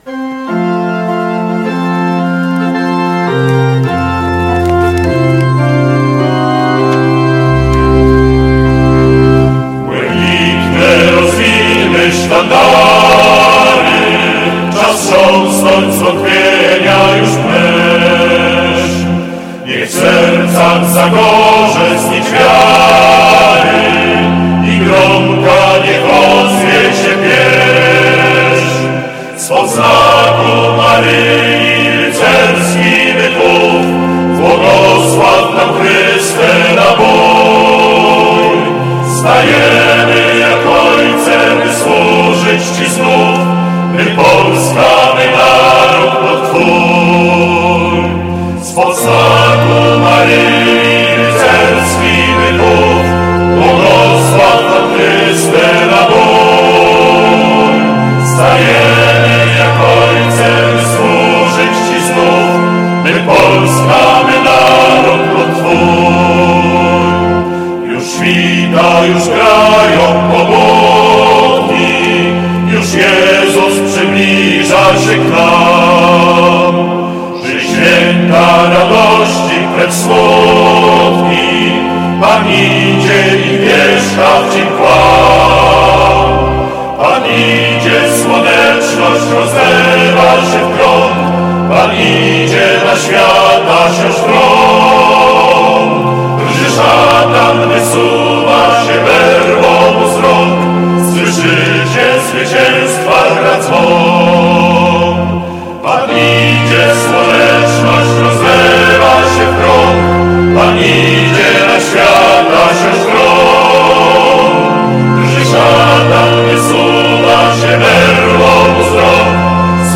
Procesja wejścia podczas uroczystej Mszy Świętej w Święto Niepodległości w Świątyni Opatrzności Bożej
Wierni mogli wysłuchać nieczęsto wykonywaną patriotyczną pieśń Błękitne rozwińmy sztandary